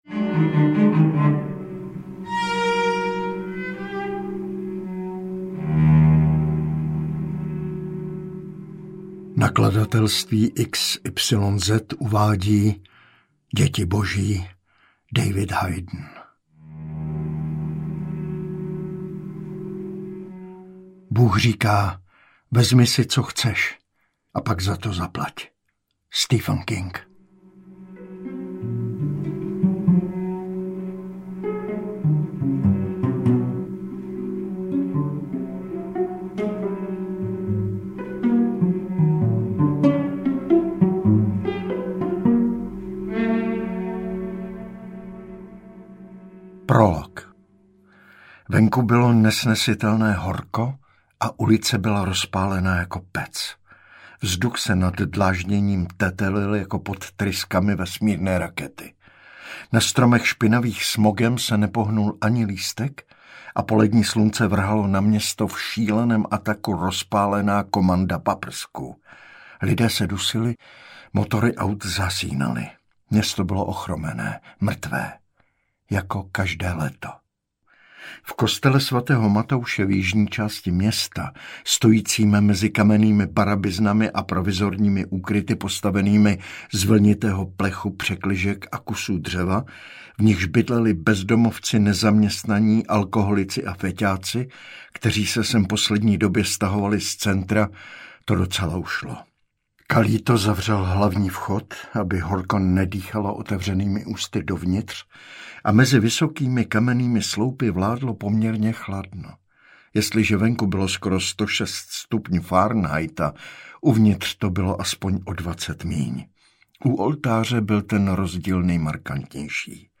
Děti boží audiokniha
Ukázka z knihy